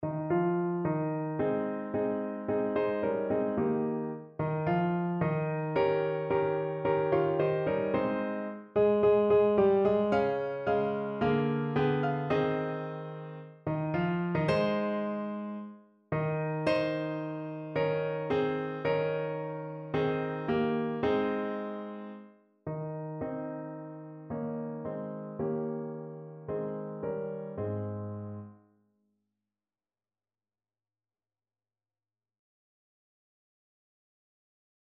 Notensatz (4 Stimmen gemischt)
• gemischter Chor [MP3] 544 KB Download